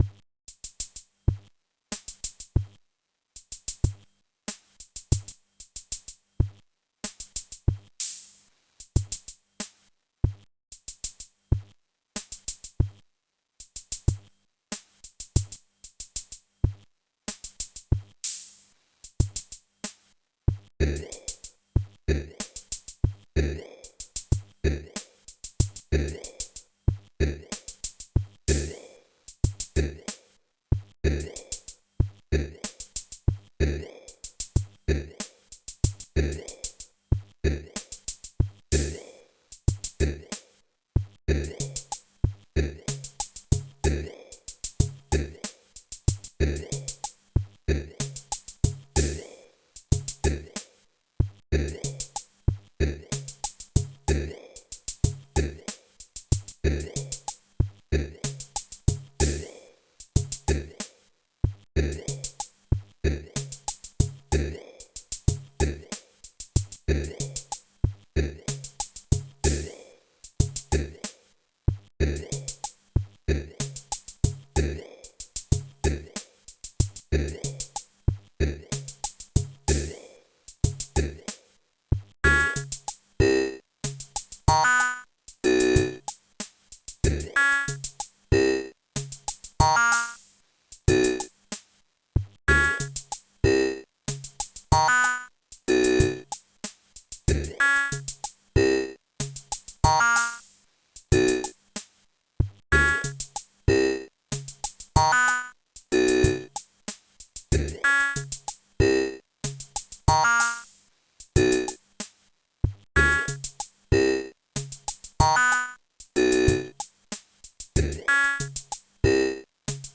Remark: Main theme for an unmade video game.